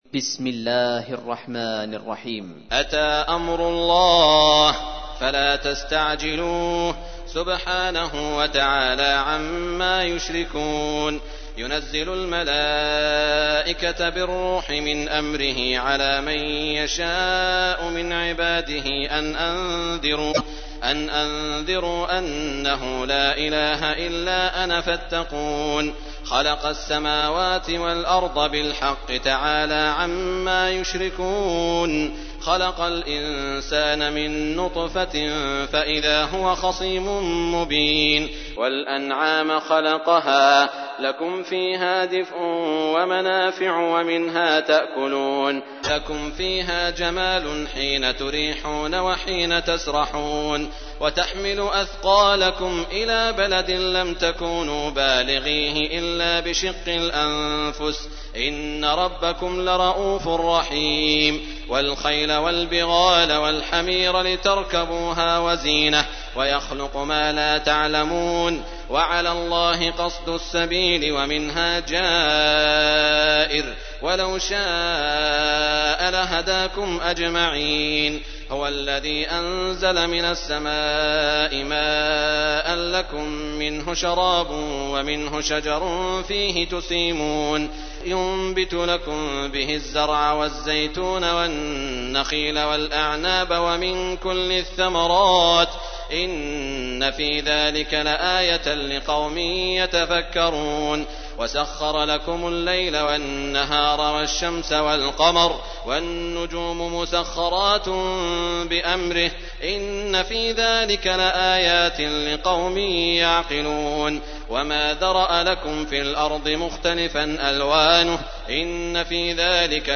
تحميل : 16. سورة النحل / القارئ سعود الشريم / القرآن الكريم / موقع يا حسين